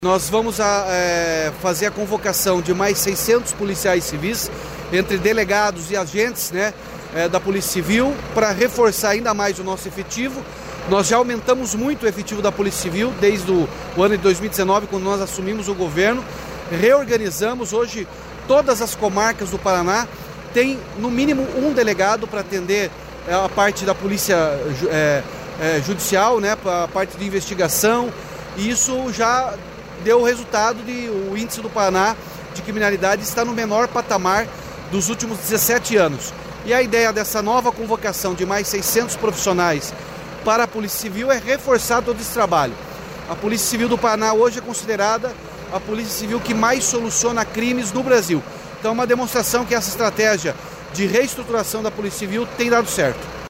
Sonora do governador Ratinho Junior sobre a contratação de 620 novos profissionais para a Polícia Civil